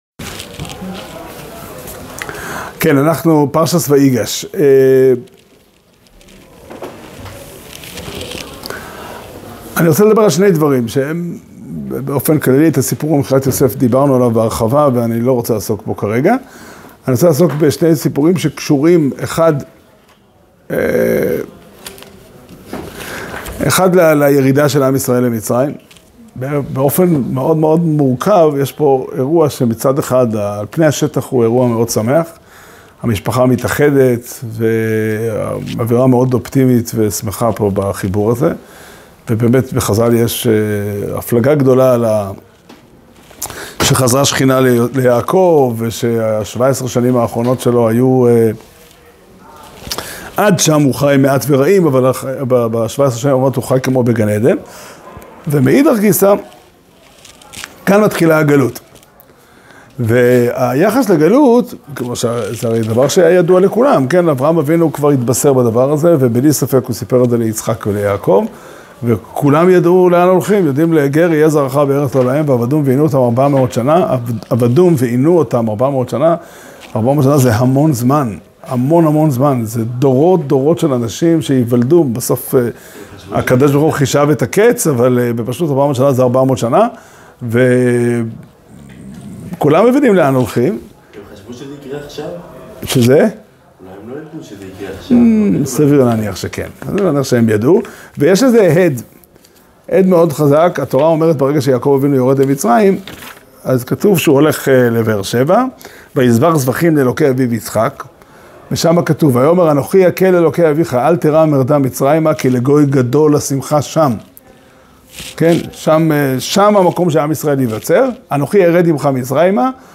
שיעור שנמסר בבית המדרש פתחי עולם בתאריך ב' טבת תשפ"ה